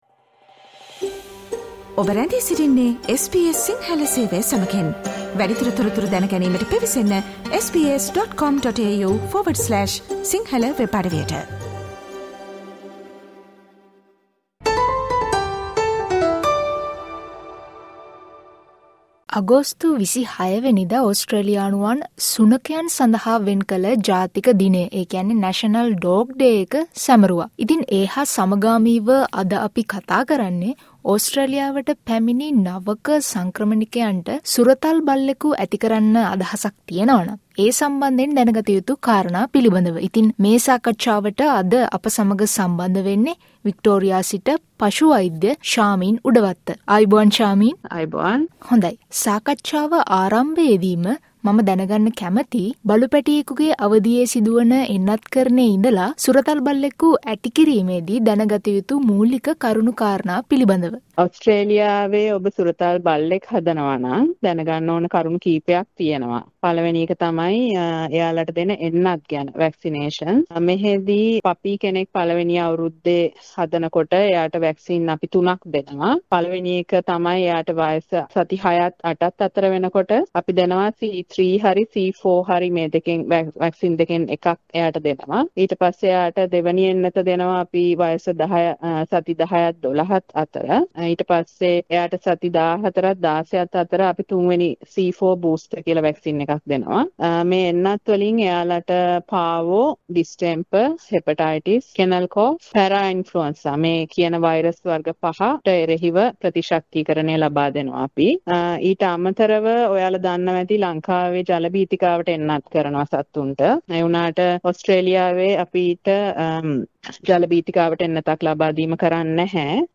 එයට සමගාමිව ඕස්ට්‍රේලියාවේ සුරතල් බල්ලෙකු ඇතිදැඩි කිරීමේ දී දැනගත යුතු එන්නත්කරණය, පුහුණු කිරීම සහ පශු වෛද්‍ය සේවා වැනි මූලික කරුණු පිළිබඳ SBS සිංහල සේවය කළ සාකච්ඡාවට සවන් දෙන්න.